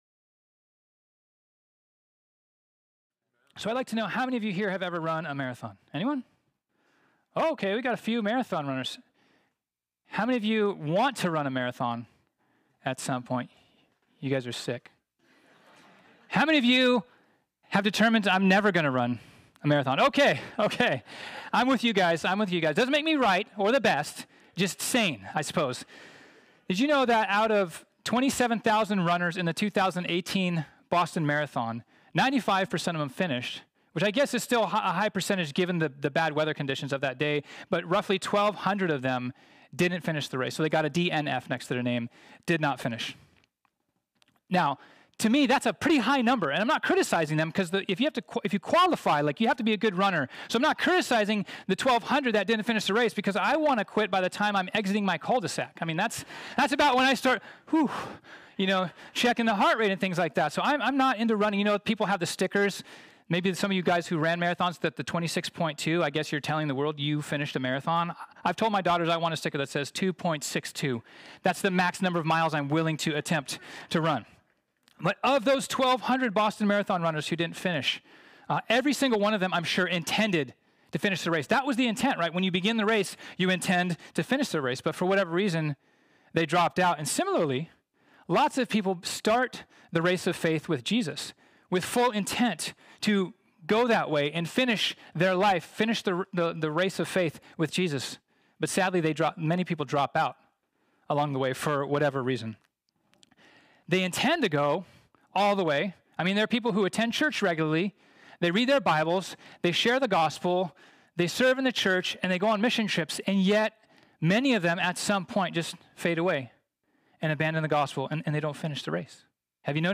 This sermon was originally preached on Sunday, August 11, 2019.